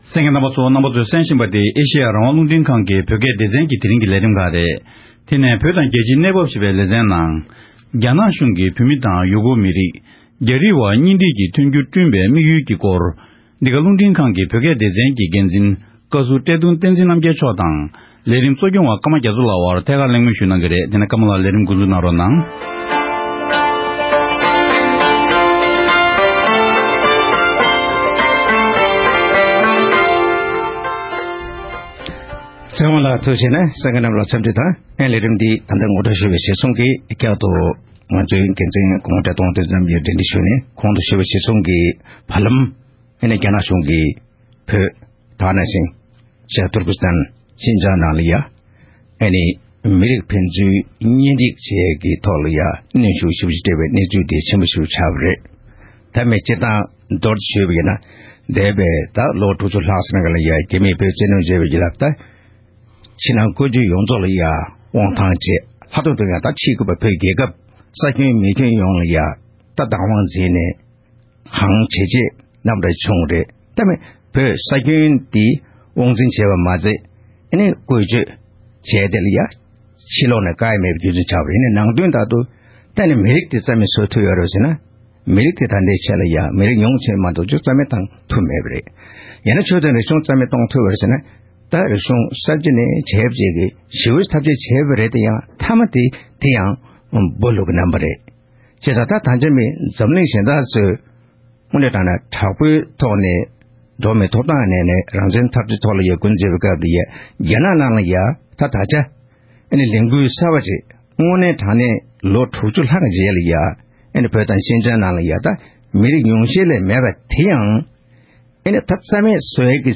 ༄༅། །ཐེངས་འདིའི་བོད་དང་རྒྱལ་སྤྱིའི་གནས་བབ་ཞེས་པའི་ལེ་ཚན་ནང་། རྒྱ་ནག་གཞུང་གིས་བོད་མི་དང་ཡུ་གུར་མི་རིགས་རྒྱ་རིགས་དབར་གཉེན་སྒྲིག་གི་མཐུན་འགྱུར་བསྐྲུན་པའི་དམིགས་ཡུལ་གྱི་སྐོར་བཀའ་བློན་ཁྲི་ཟུར་བཀྲས་མཐོང་བསྟན་འཛིན་རྣམ་རྒྱལ་མཆོག་དང་ལྷན་དུ་གླེང་མོལ་ཞུས་པ་ཞིག་གསན་རོགས་གནང་།།